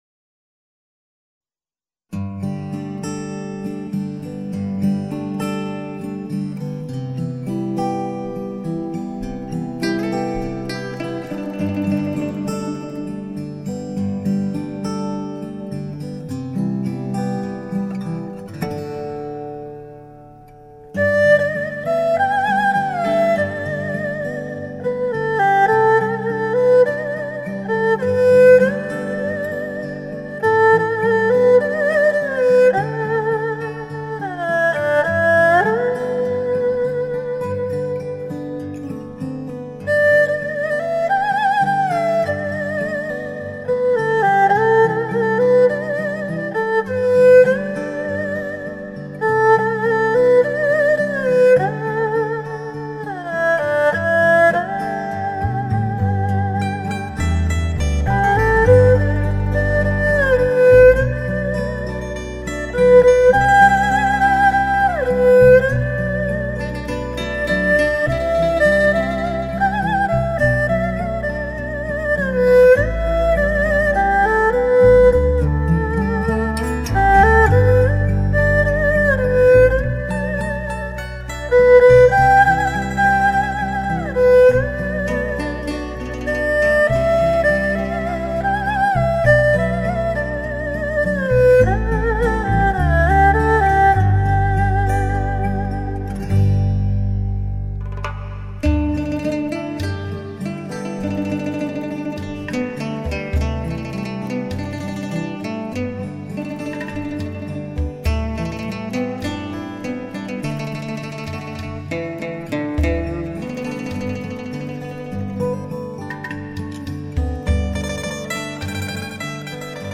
风格甚为古朴、儒雅
二胡音像“形态”丰满厚实，音色甜润醇和而且胆味浓郁，质感之真实犹如亲临录音现场。